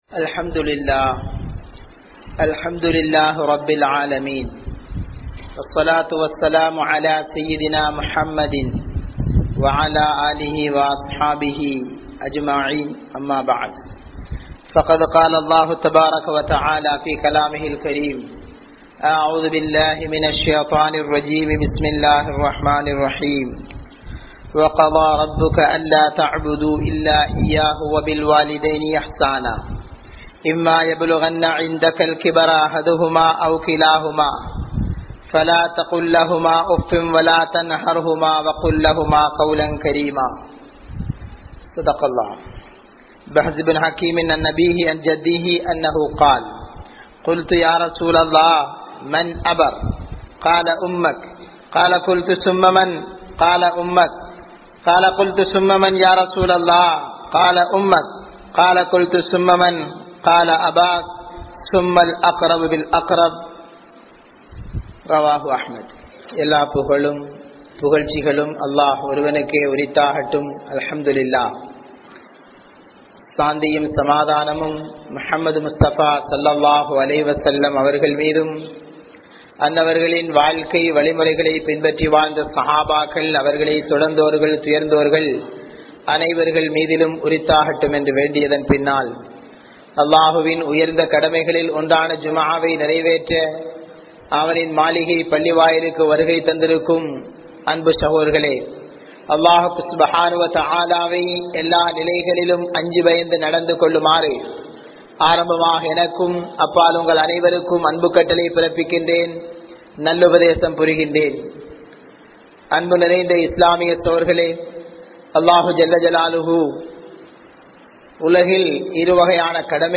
Kulanthaihalin Kadamaihal (குழந்தைகளின் கடமைகள்) | Audio Bayans | All Ceylon Muslim Youth Community | Addalaichenai